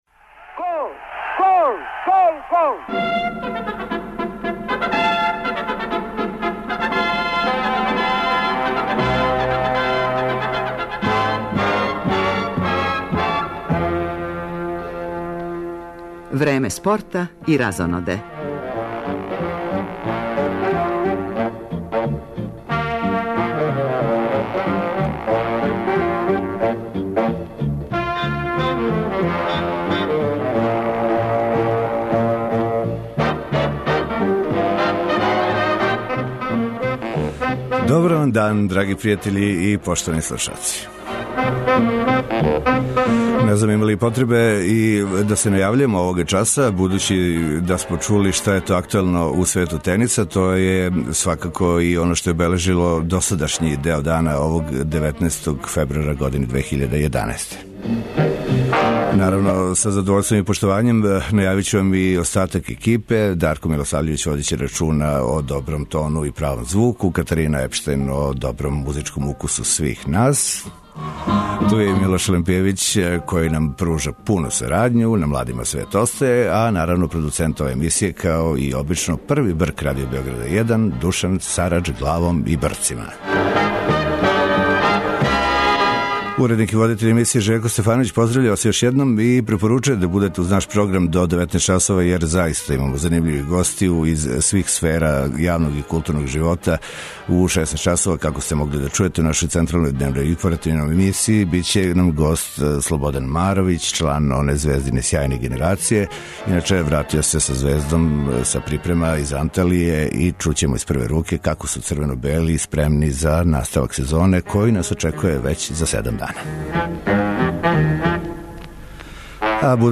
Током емисије,имаћемо редовна репортерска јављања са првог меча данашњег програма.